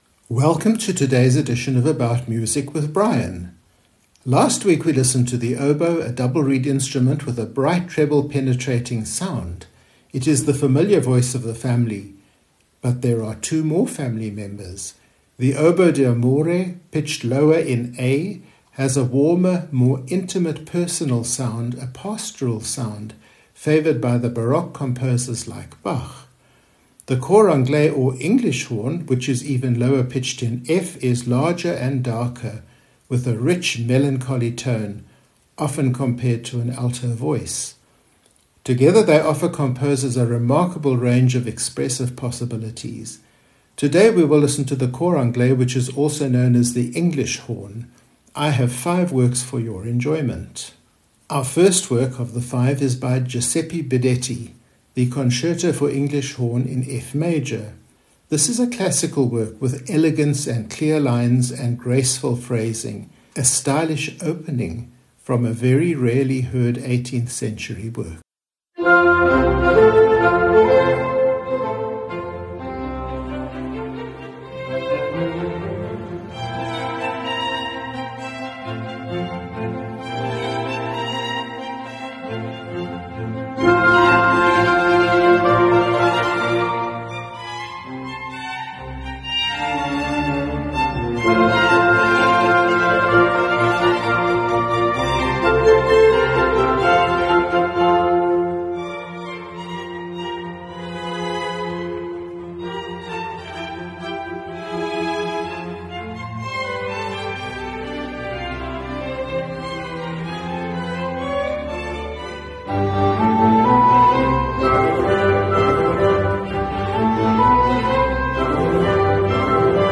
The cor anglaise or English Horn, pitched in F, is larger and darker, with a rich, melancholy tone often compared to an alto voice.